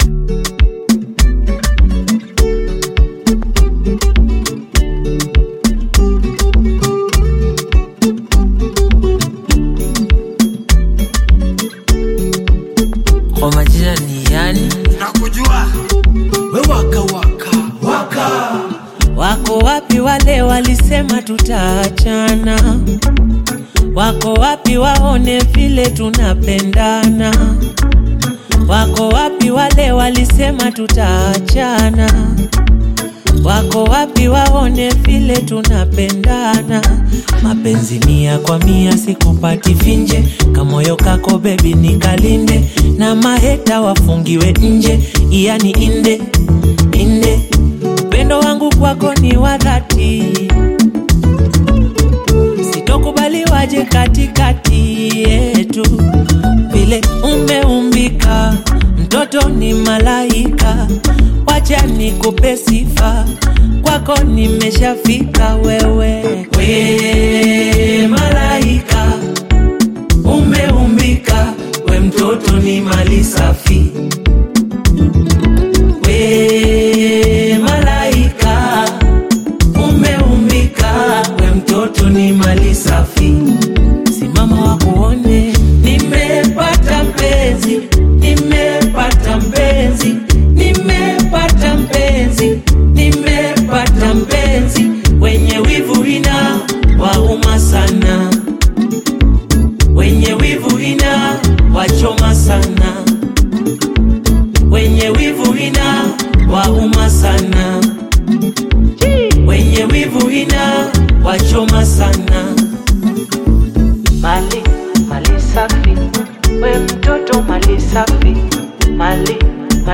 Afro-pop
smooth vocals